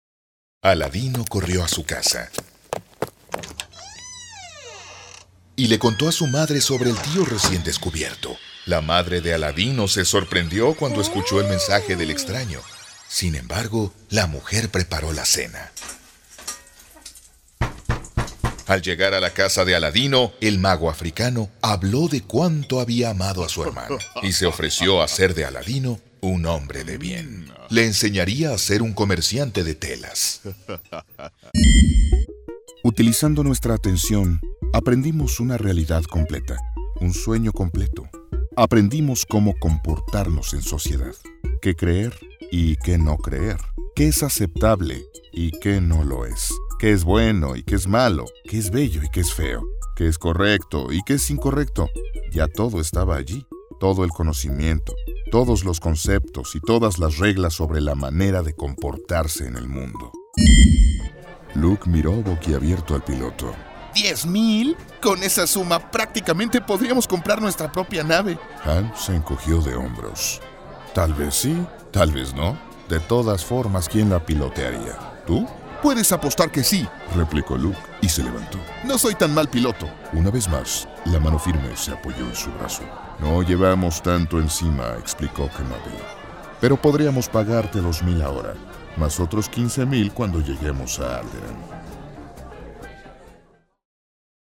DEMO AUDIO LIBROS_2.mp3